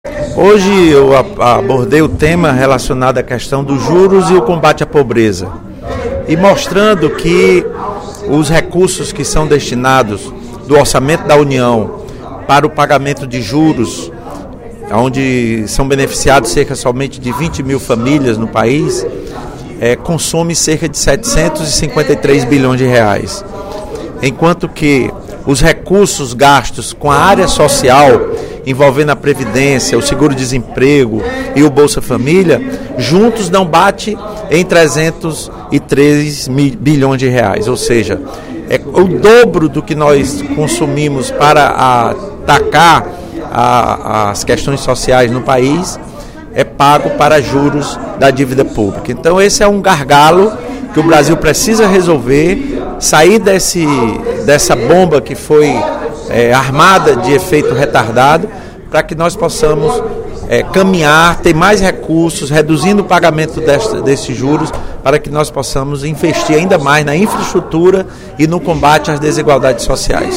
No primeiro expediente da sessão plenária desta terça-feira (26/02), o deputado Lula Morais (PCdoB) destacou os benefícios dos programas sociais do governo petista de combate à pobreza.